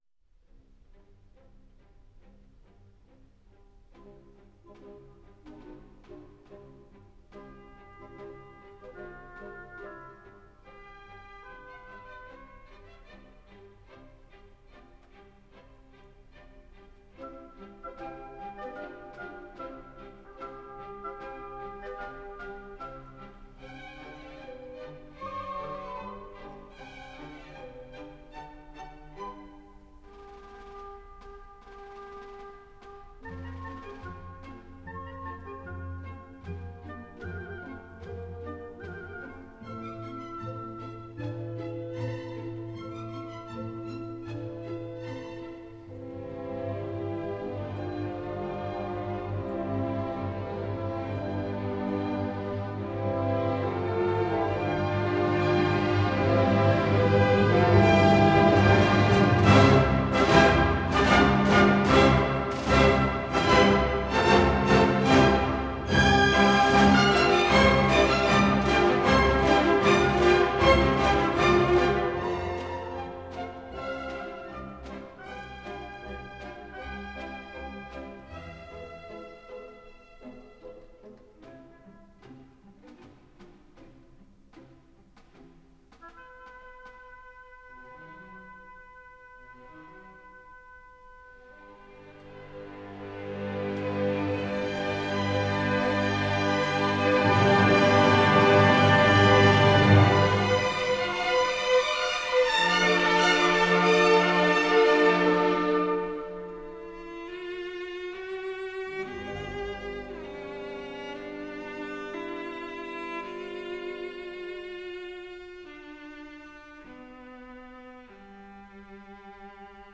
Vals 'El Emperador' Op.437